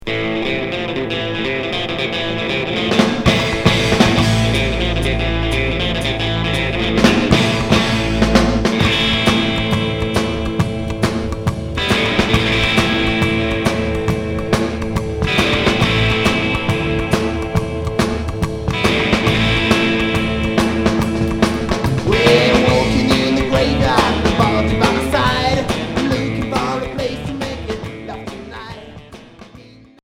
Psychobilly